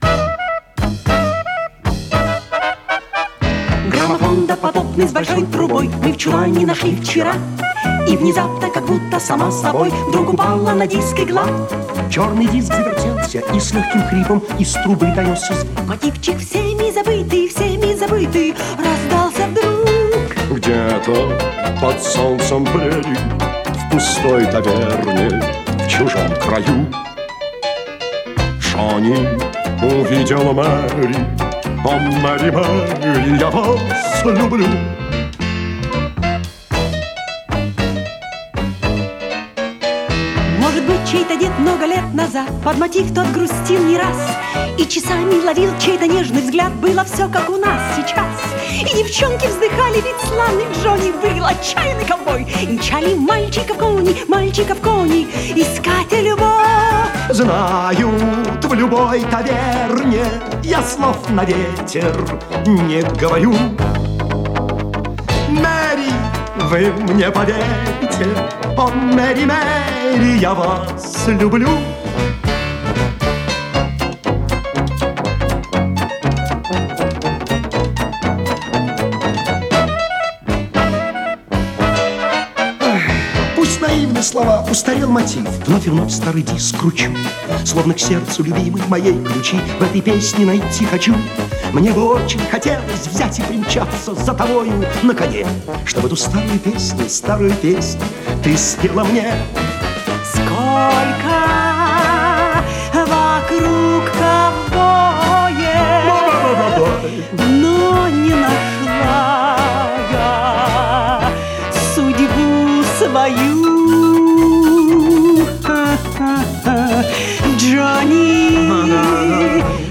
ПодзаголовокМузыкальный шарж
ВариантДубль моно